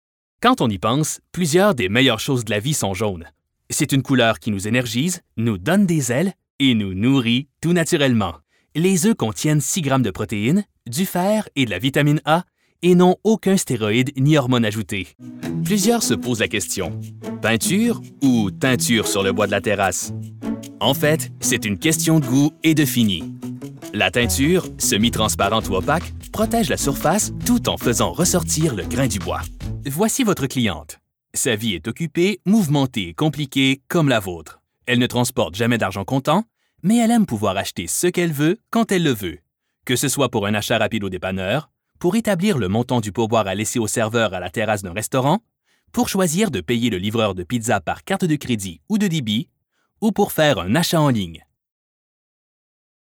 Male
30s, 40s
French Canadian (Native) , American English
Confident, Cool, Corporate, Engaging, Friendly
Commercial.mp3
Microphone: Neumann TLM103
Audio equipment: Pro recording Booth, StudioBricksOne, Avalon PreAmp